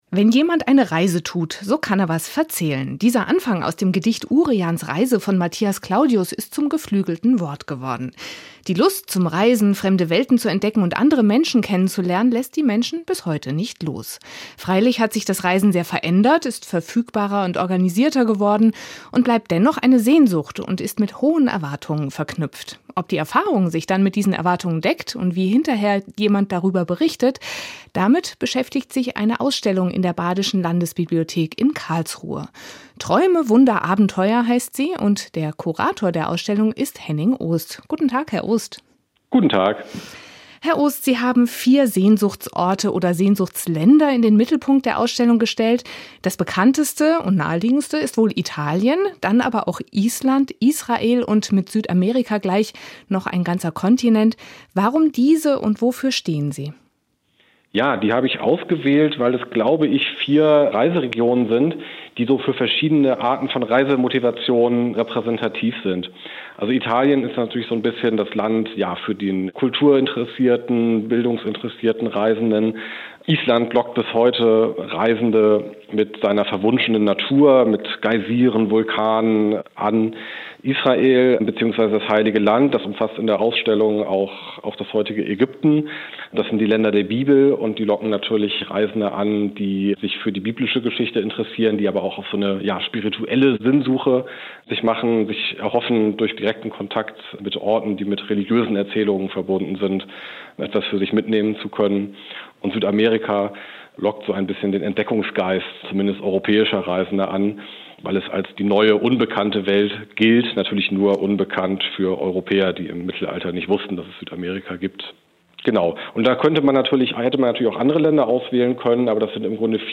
ausstellung-zu-sehnsuchtsorten-der-reiseliteratur-in-der-badischen-landesbibliothek.mp3